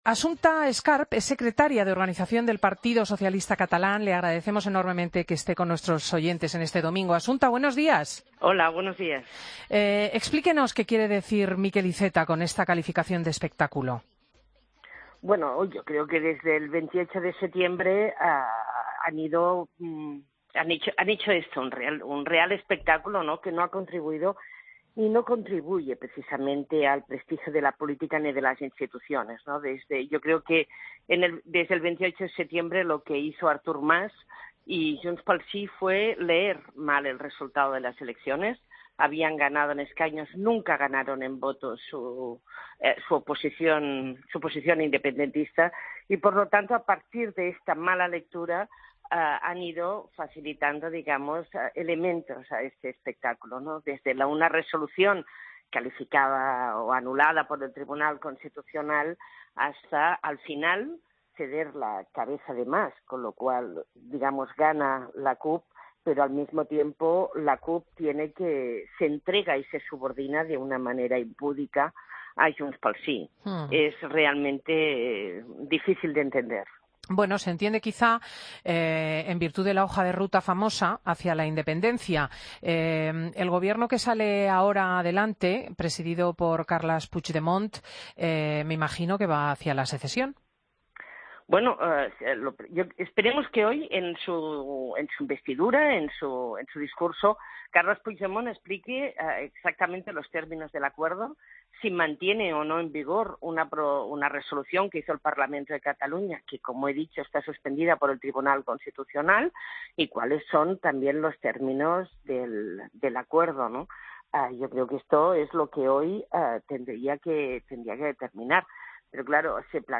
AUDIO: Escucha la entrevista a Assumpta Escarp, Secretaria de Organización del PSC, en Fin de Semana.